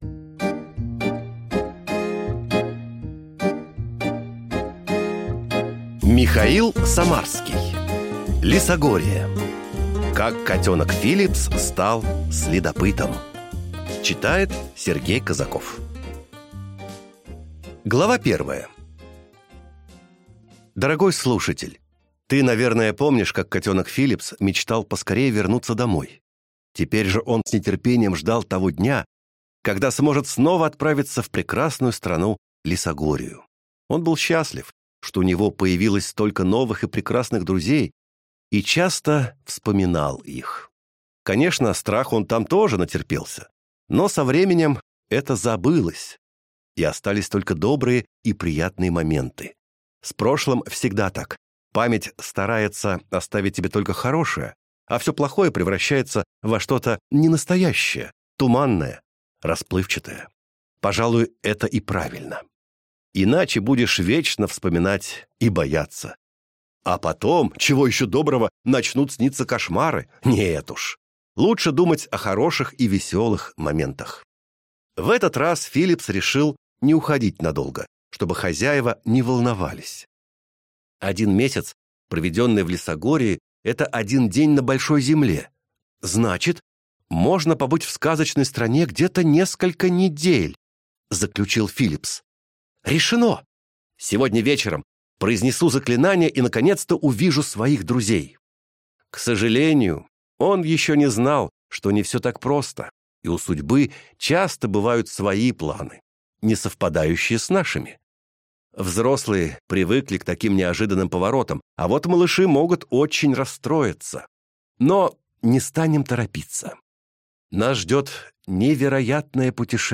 Аудиокнига Лесогория. Как котёнок Филипс стал следопытом | Библиотека аудиокниг